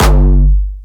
Jumpstyle Kick Solo